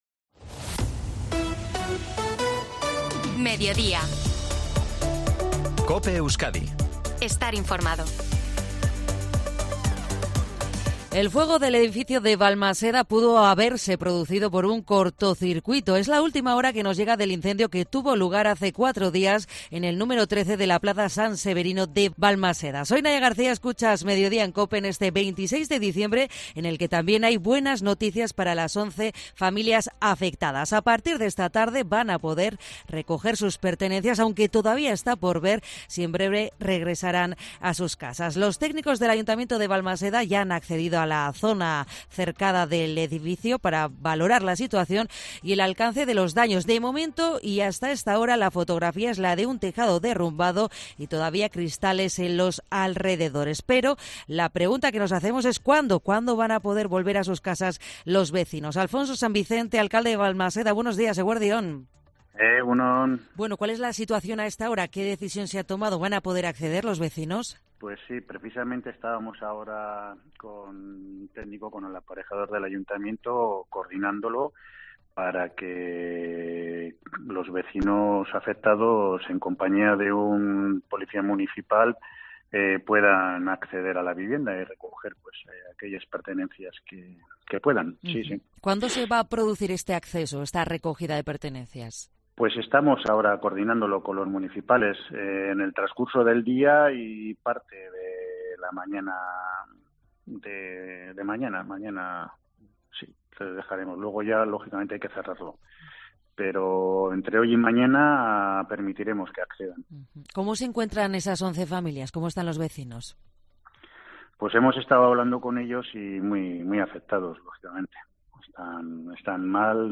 Entrevista a Alfonso San Vicente, alcalde de Balmaseda, en COPE Euskadi